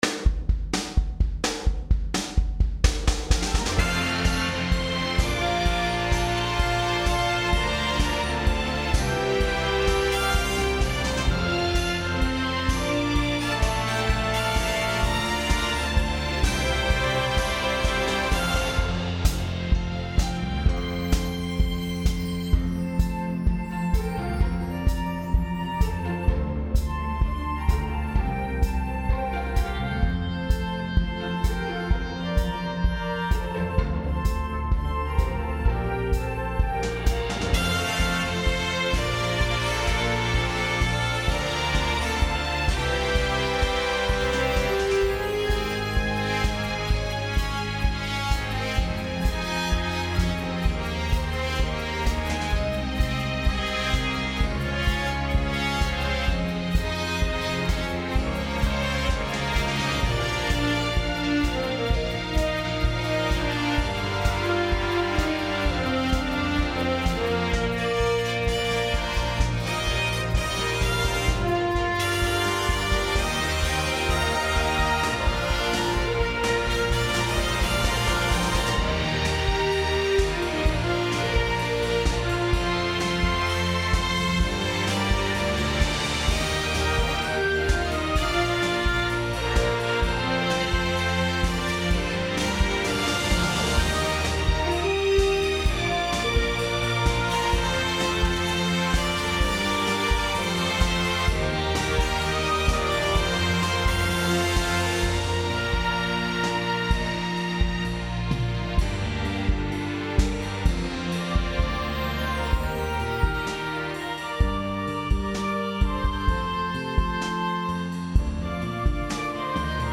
powerful declaration of faith
high-energy service opener